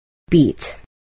Beat.wav